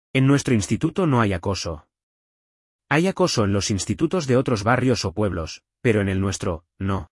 Creado por IA con CANVA